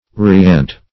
Riant \Ri`ant"\, a. [F. riant, p. pr. of rire to laugh, L.